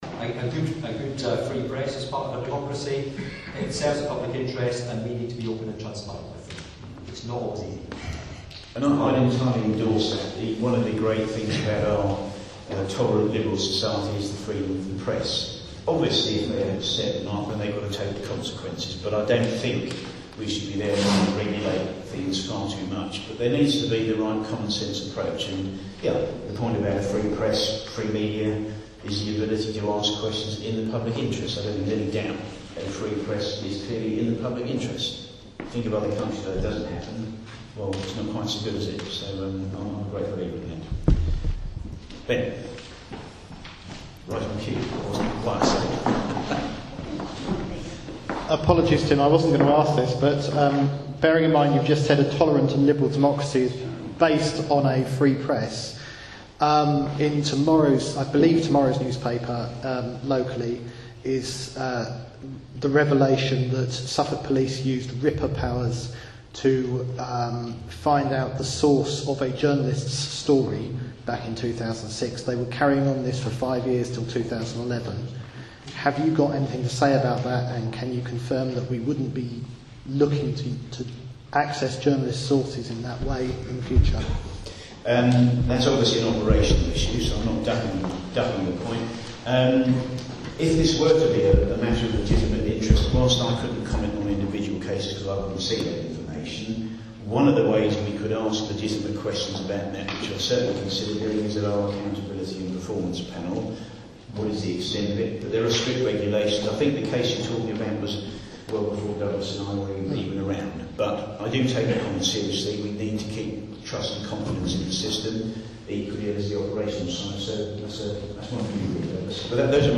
Suffolk's Police & Crime Commissioner, and Suffolk's Chief Constable, addressing a public meeting, justified the use of anti terror RIPA powers to investigate a journalists sources.